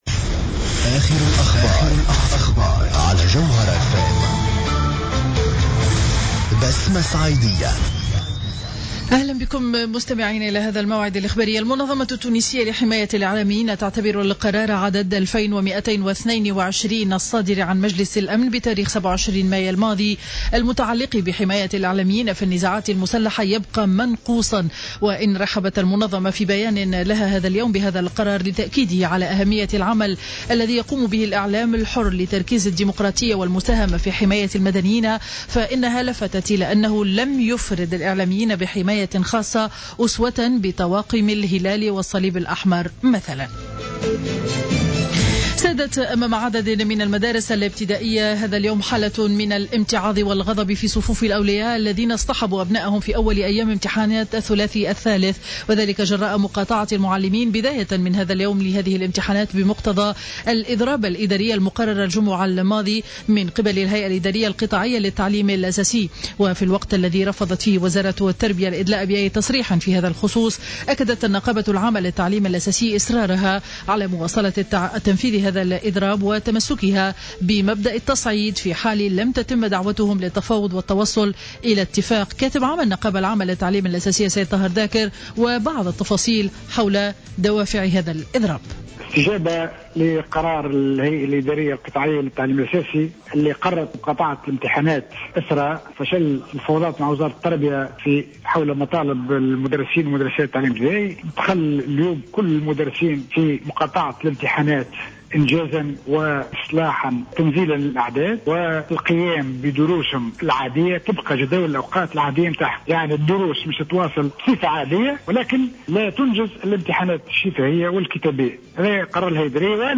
نشرة أخبار منتصف النهار ليوم الاثنين 01 جوان 2015